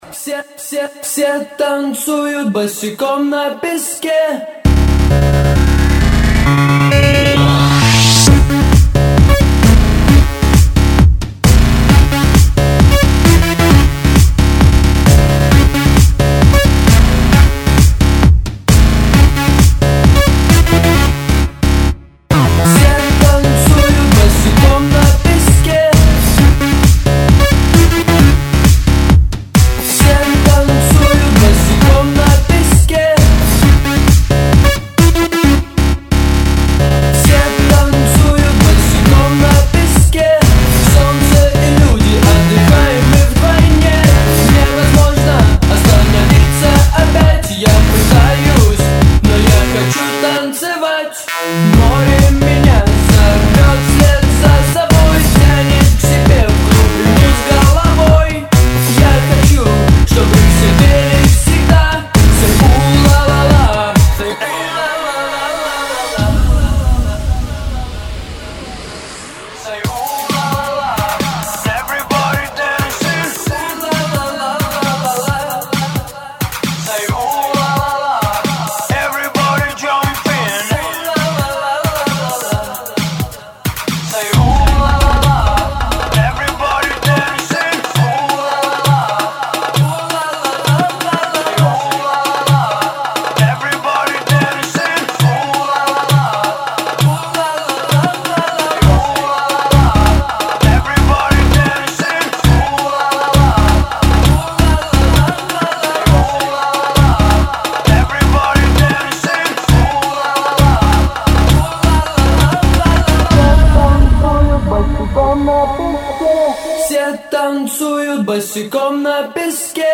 Категория: Клубняк | Добавил: Admin